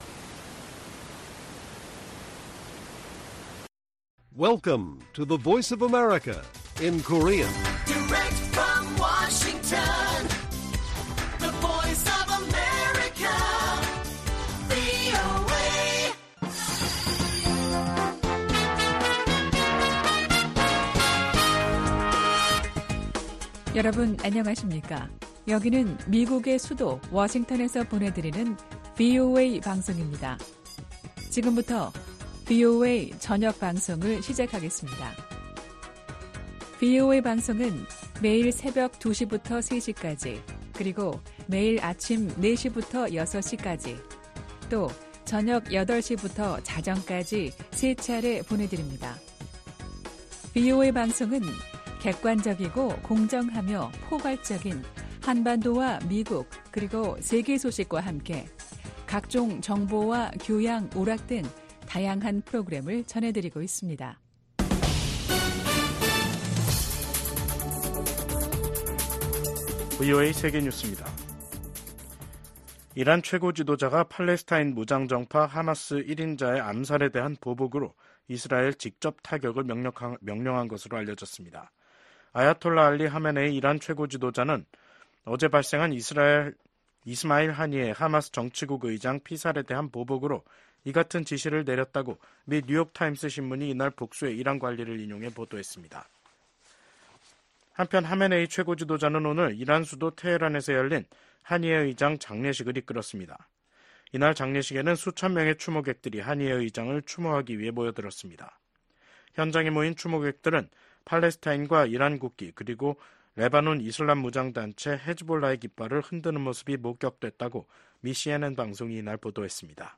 VOA 한국어 간판 뉴스 프로그램 '뉴스 투데이', 2024년 8월 8일 1부 방송입니다. 올 하반기 미한 연합훈련인 을지프리덤실드(UFS)가 오는 19일부터 실시됩니다.